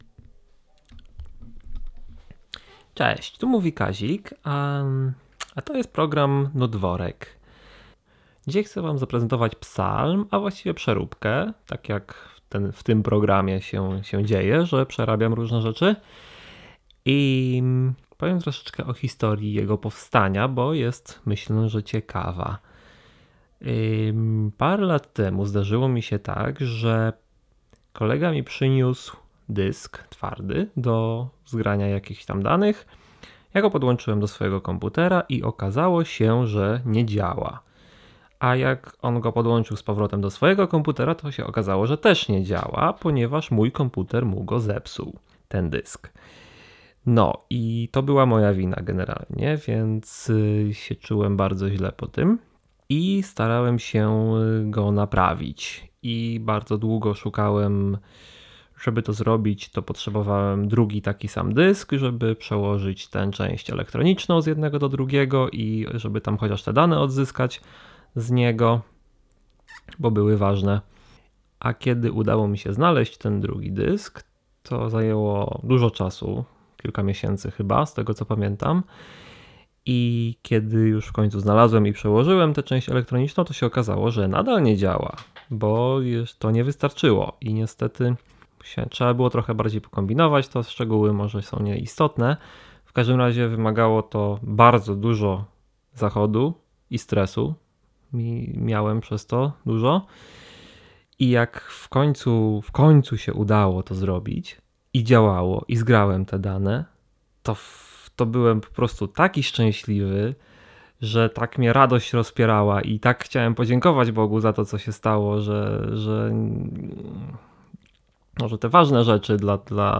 Psalm w wersji komputerowej. Bo informatyk też ma czasem powód, żeby dziękować Bogu.